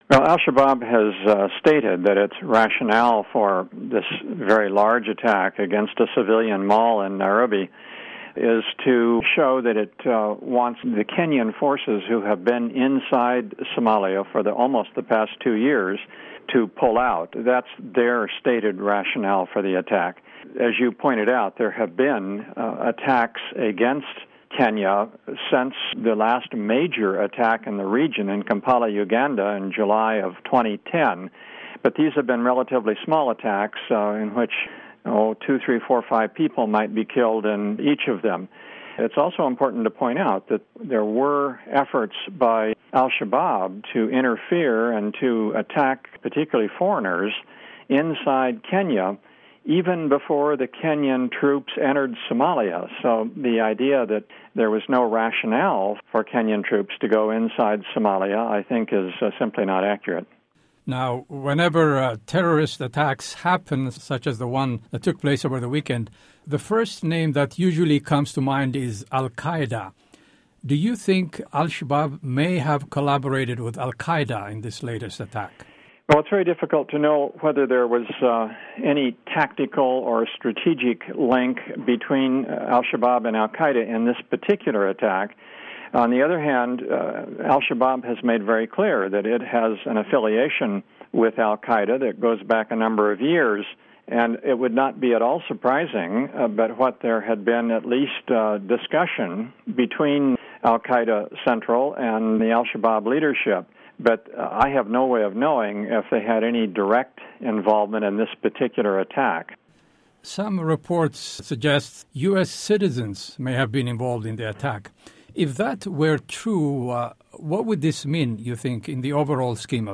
Listen to interview with former ambassador David Shinn